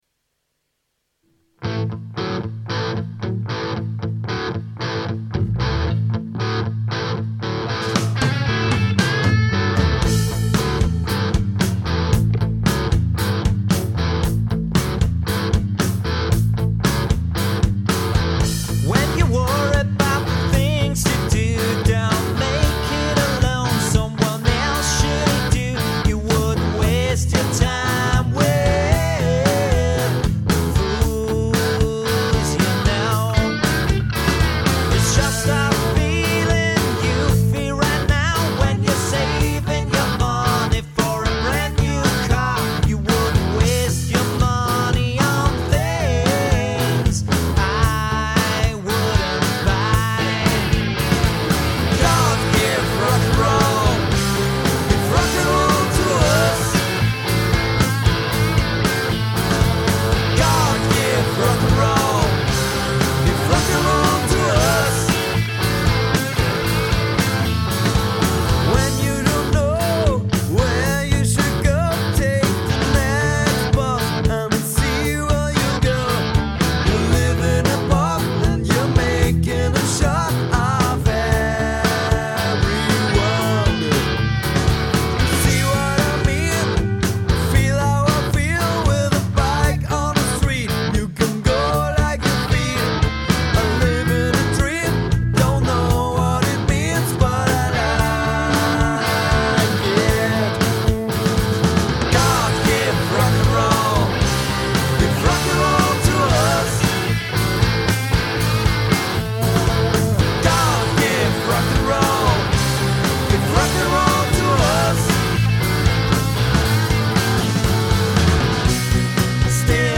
Rock´n Roll-Coverband aus NÖ